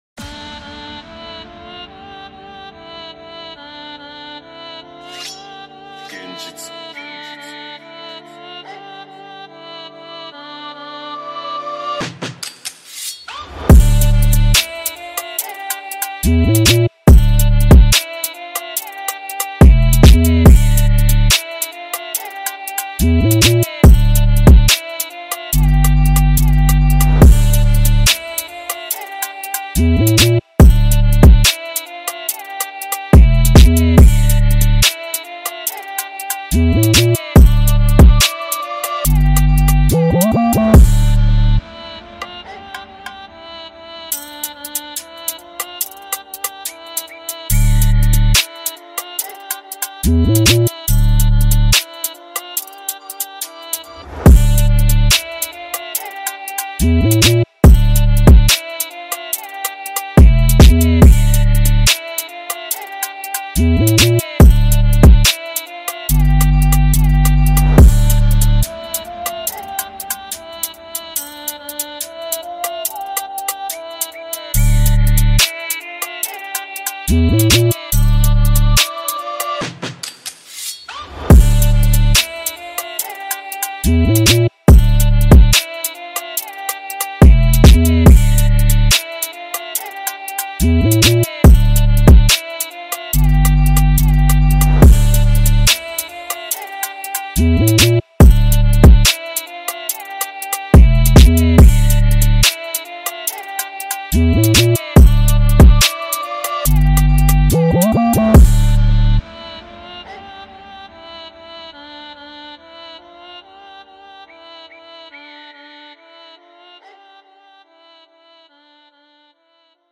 Instru Drill